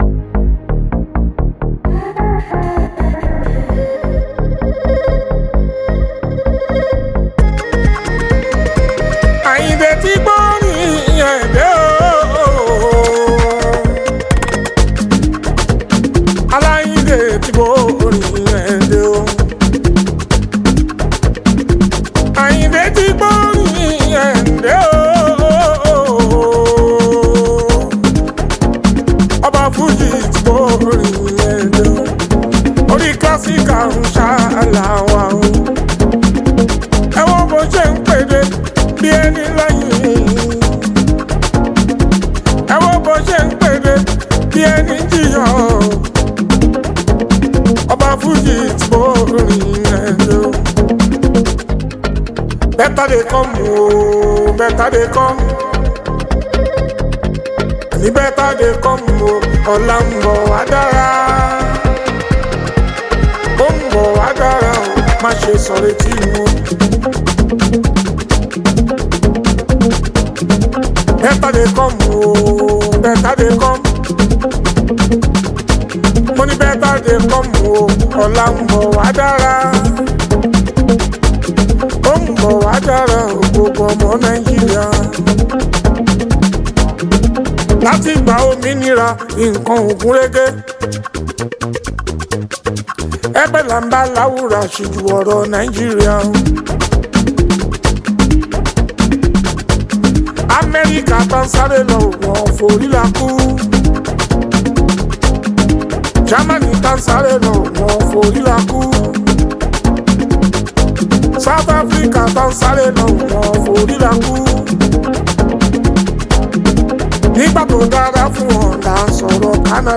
Fuji, Highlife
Nigerian Yoruba Fuji track